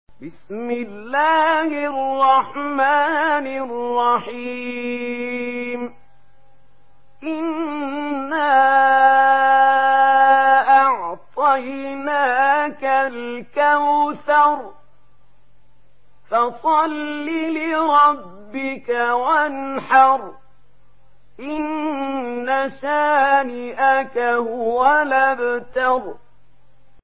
Surah আল-কাউসার MP3 in the Voice of Mahmoud Khalil Al-Hussary in Warsh Narration
Surah আল-কাউসার MP3 by Mahmoud Khalil Al-Hussary in Warsh An Nafi narration.